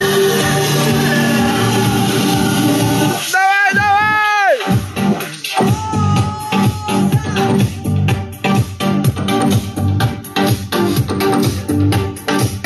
караоке мінус